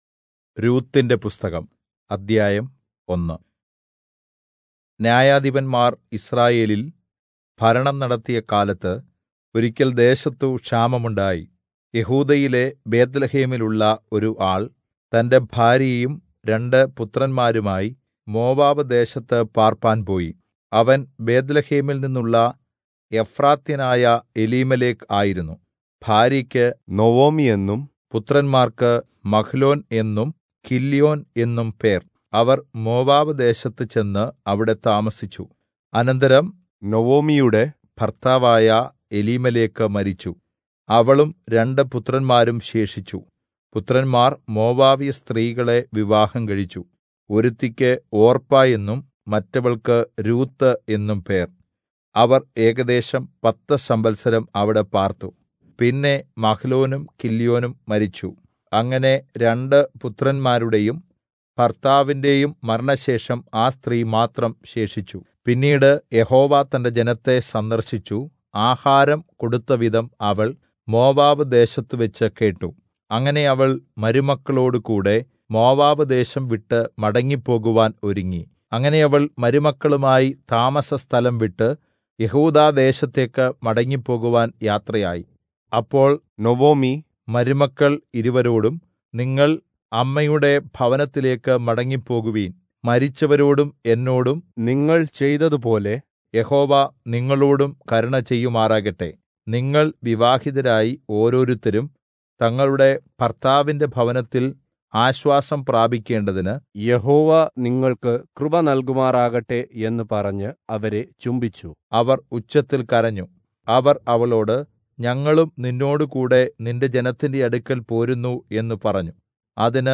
Malayalam Audio Bible - Ruth 4 in Irvml bible version